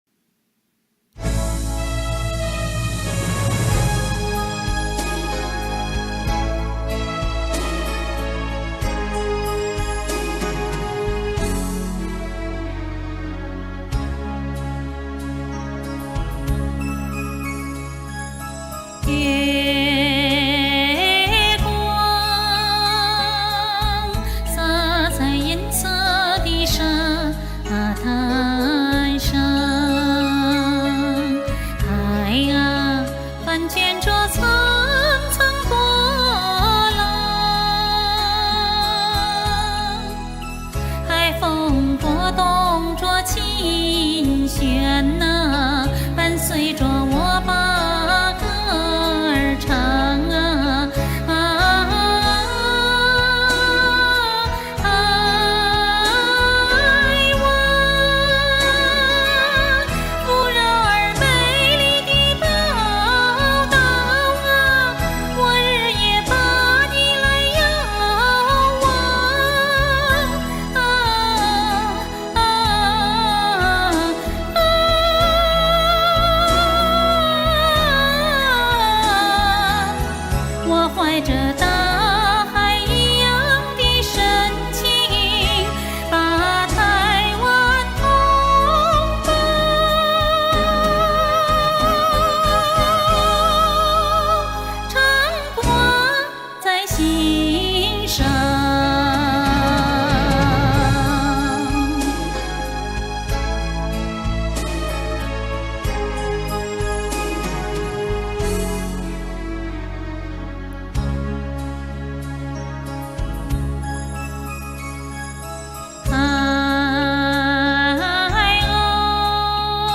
好甜美干净的声音！